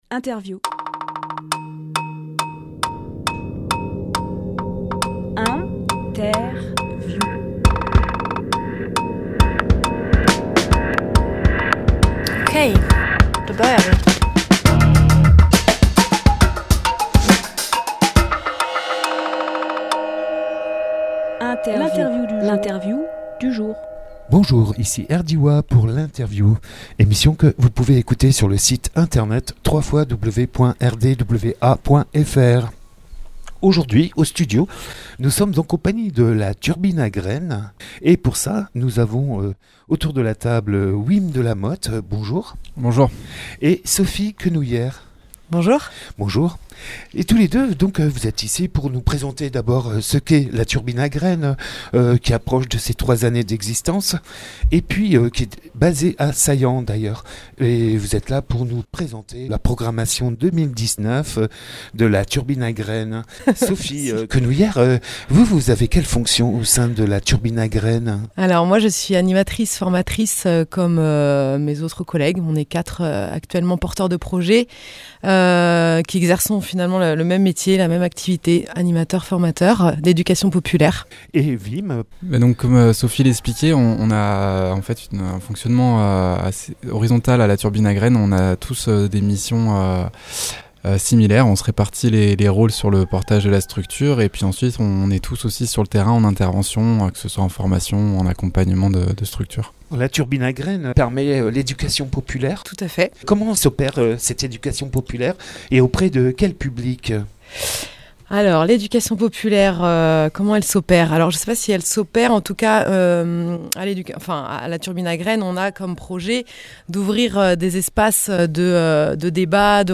Emission - Interview Education populaire avec la Turbine à graines Publié le 17 octobre 2018 Partager sur…
Lieu : Studio RDWA